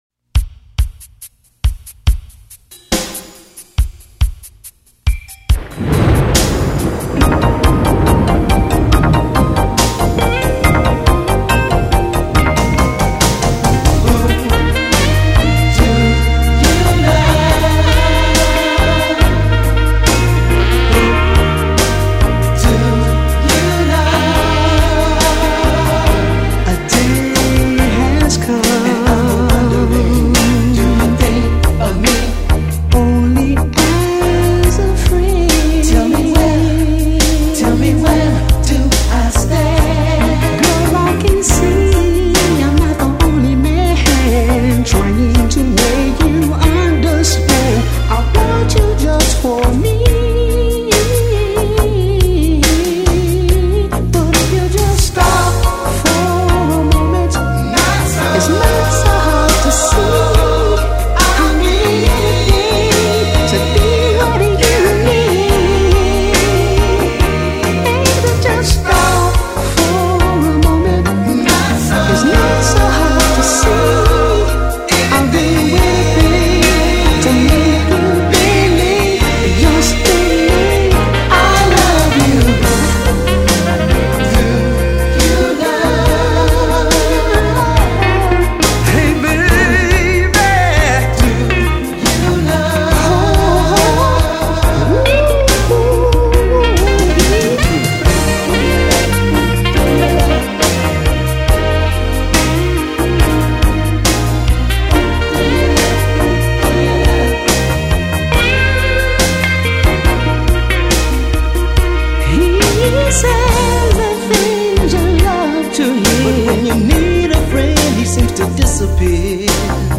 unique focal style and range
pure music and vocal-ship